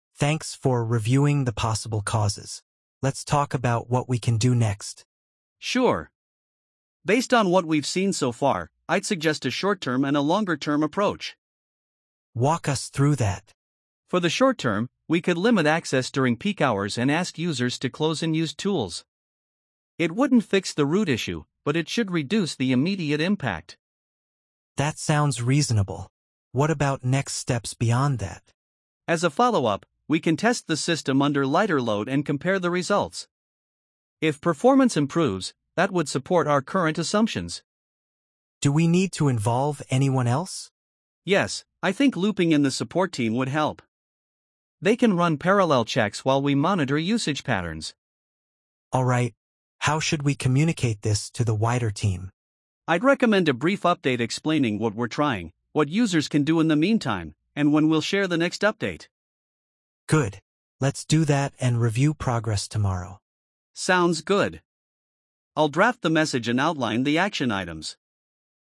🤝 A team discusses solutions after identifying a problem.